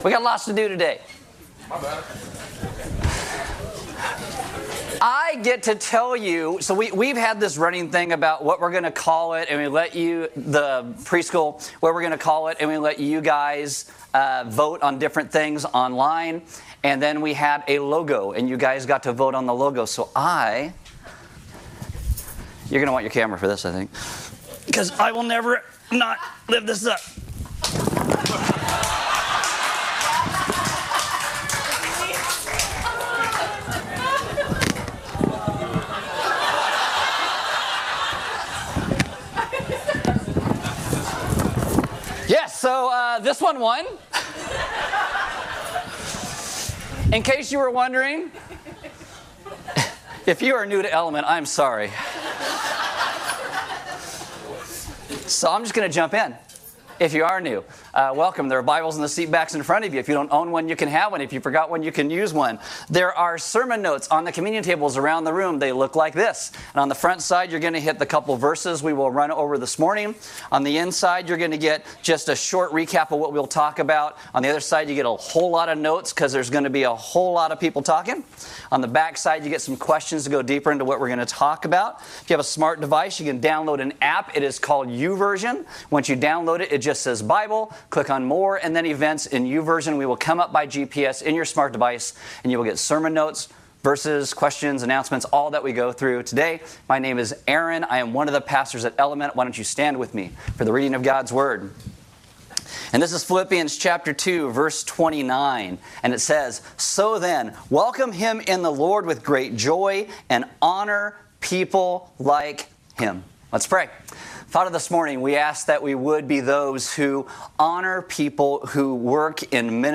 Check back soon for all archived sermons prior to 2021.